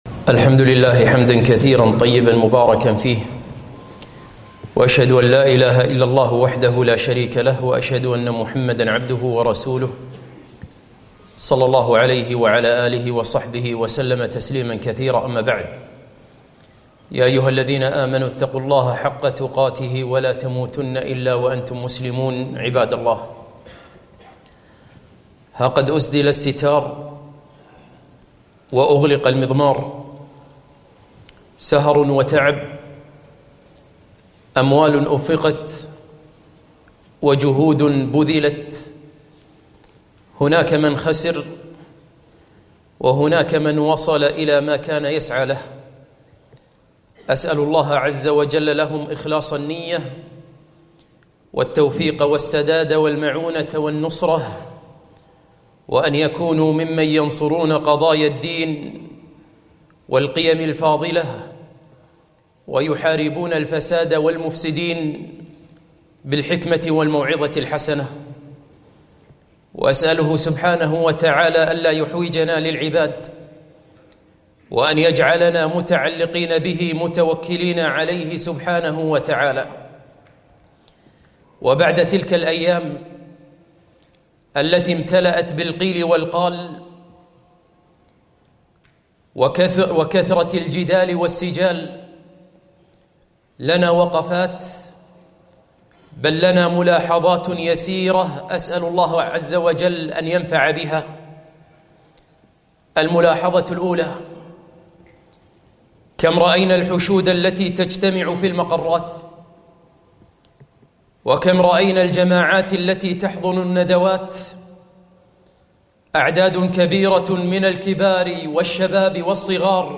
خطبة - ملاحظات بعد الانتخابات